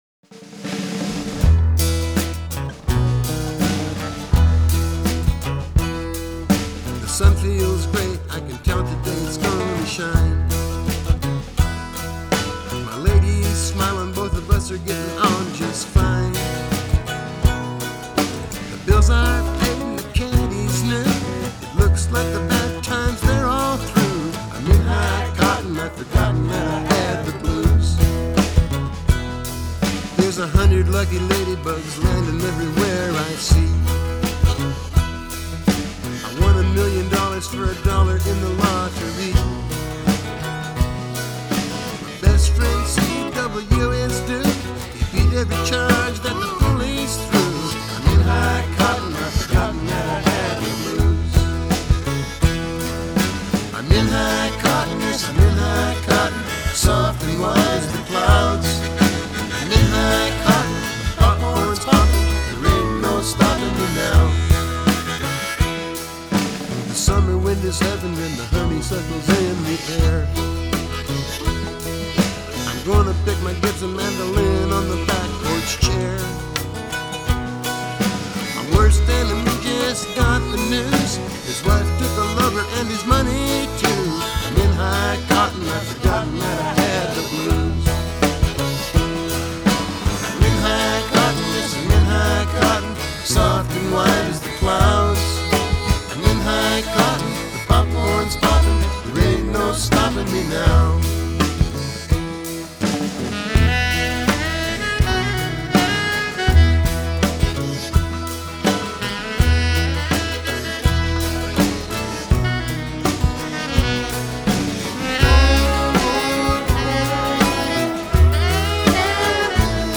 Жанр: Country Rock